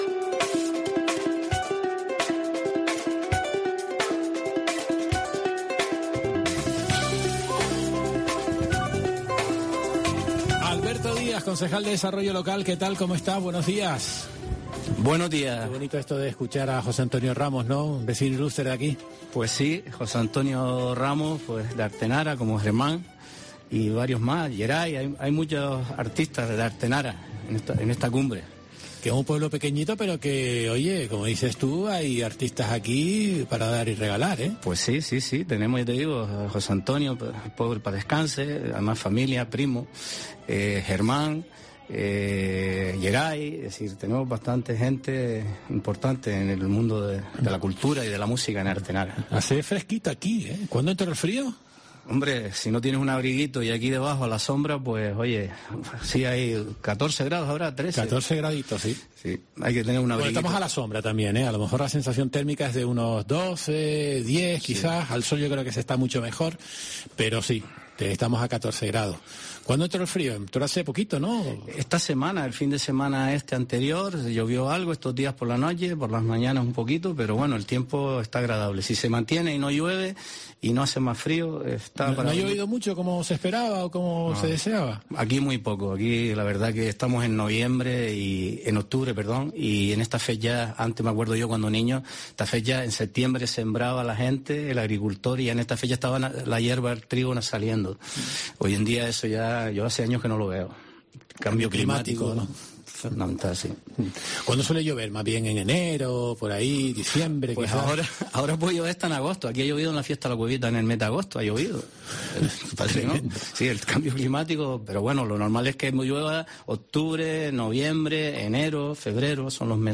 Alberto Díaz, concejal de Desarrollo Local